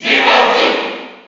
Zero_Suit_Samus_Cheer_NTSC_SSB4.ogg